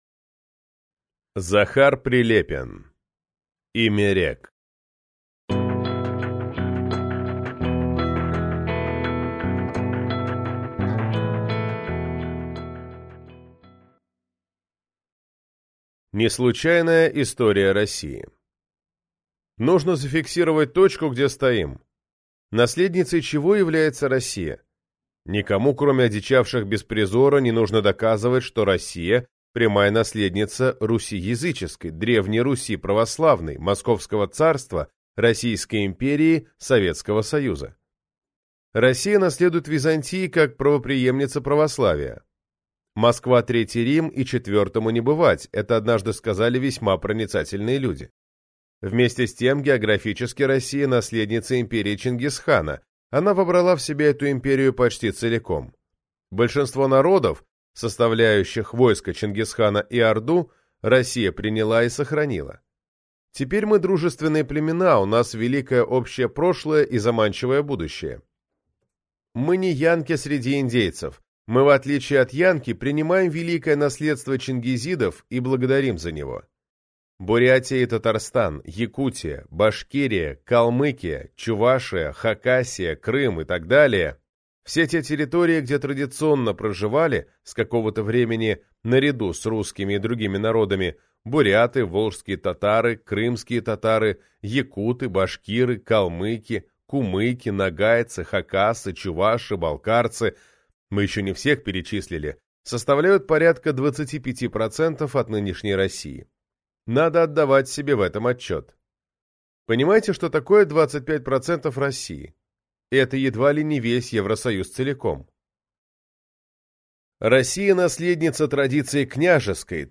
Аудиокнига Имя рек. 40 причин поспорить о главном - купить, скачать и слушать онлайн | КнигоПоиск